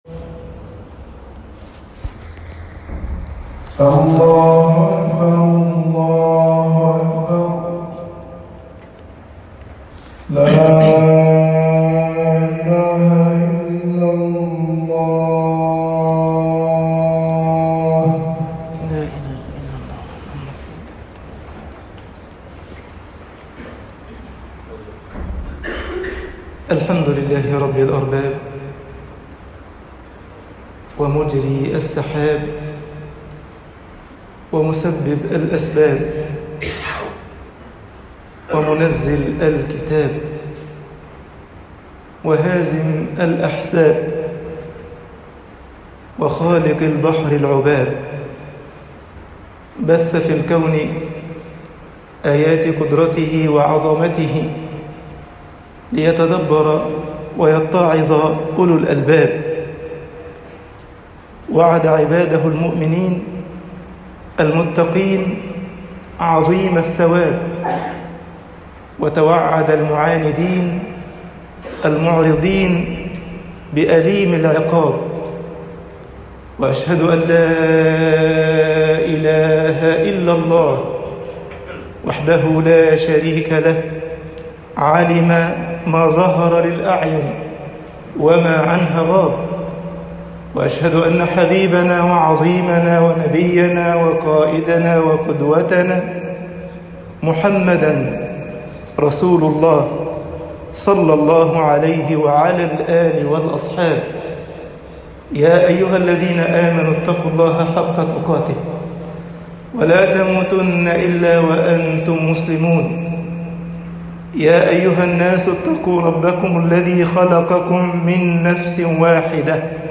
خطب الجمعة - مصر الْإسْلَامُ دِينٌ يَبْعَثُ الْحَيَاةَ طباعة البريد الإلكتروني التفاصيل كتب بواسطة